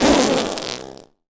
Sfx Suicidepop Sound Effect
sfx-suicidepop-2.mp3